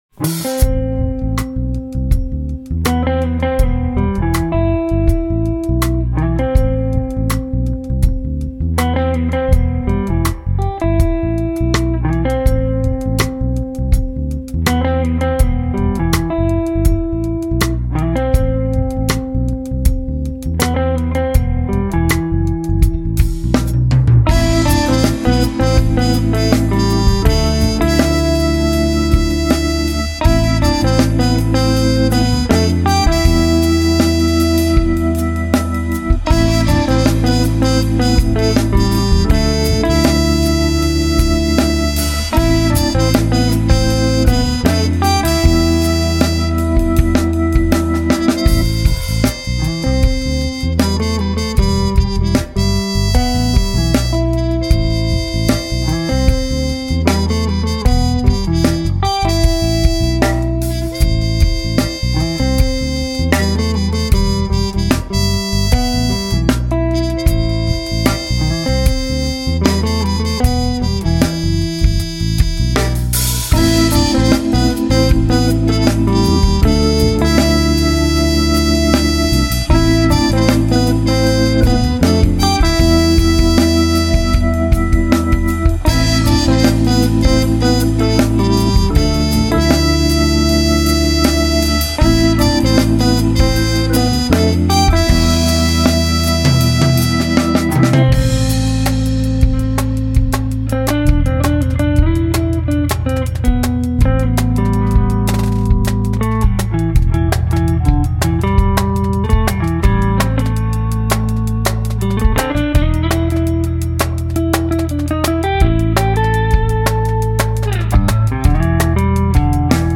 Žánr: Jazz/Blues
mixující moderní taneční rytmy s jazzovou hudbou.